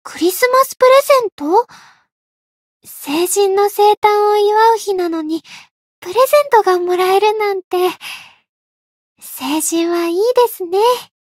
灵魂潮汐-伊汐尔-圣诞节（送礼语音）.ogg